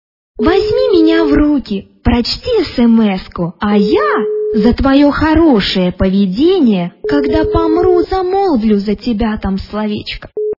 - звуки для СМС